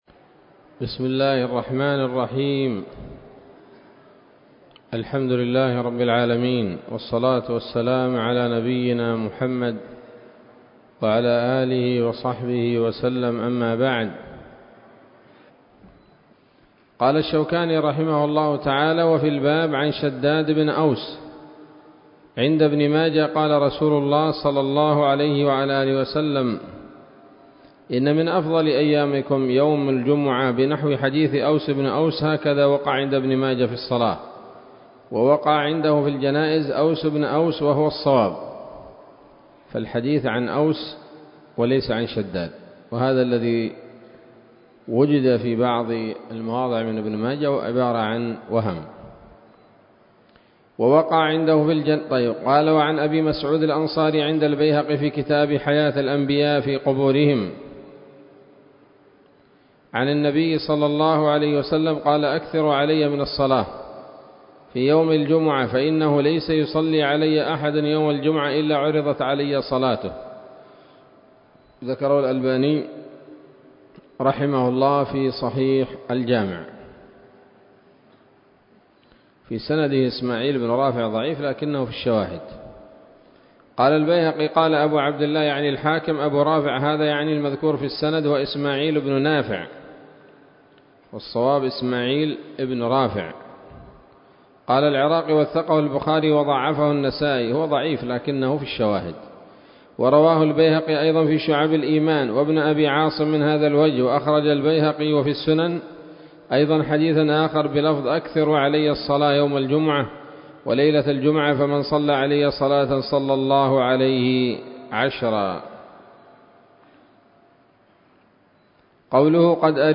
الدرس الخامس عشر من ‌‌‌‌أَبْوَاب الجمعة من نيل الأوطار